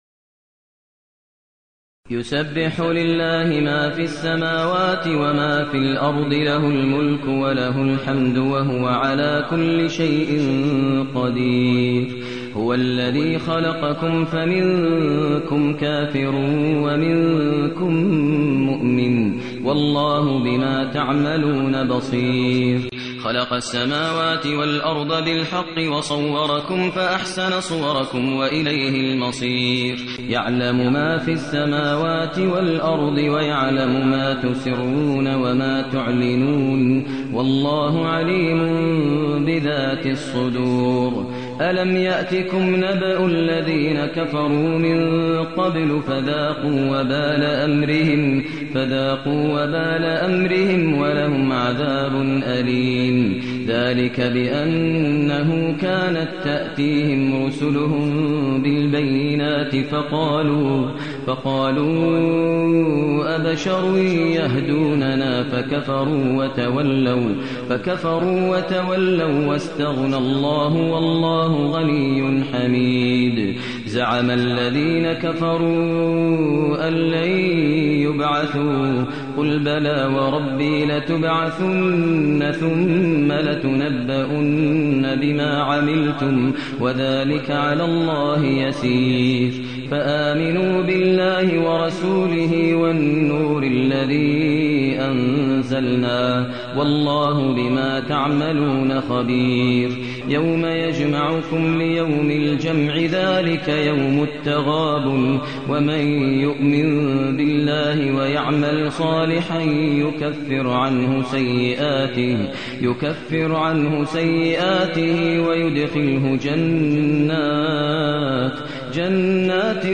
المكان: المسجد النبوي الشيخ: فضيلة الشيخ ماهر المعيقلي فضيلة الشيخ ماهر المعيقلي التغابن